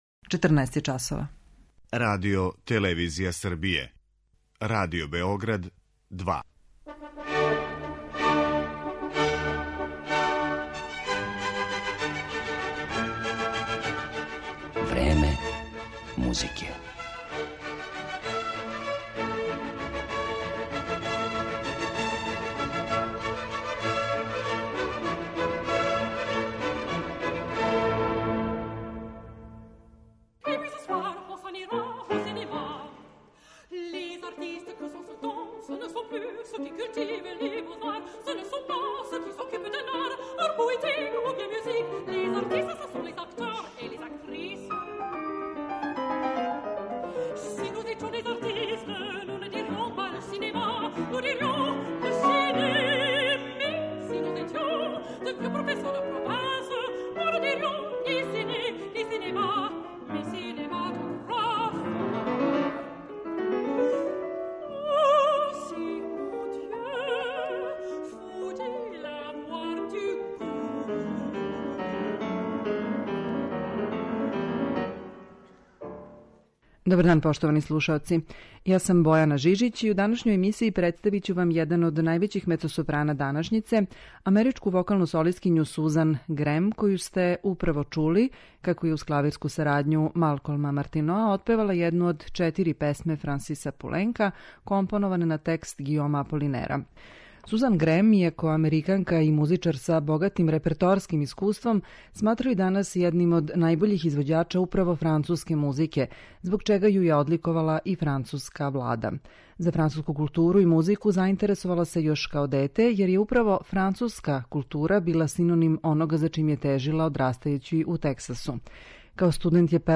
Једном од највећих мецосопрана данашњице и чланици чувене Метрополитен опере у Њујорку, америчкој вокалној солисткињи Сузан Грем, посвећена је данашња емисија.
У данашњој емисији певаће уз клавирску сарадњу Малколма Мартиноа и изводити дела Пуленка, Дебисија, Месажеа, Глука, Брамса, Берга, Хана, Малера и Моцарта.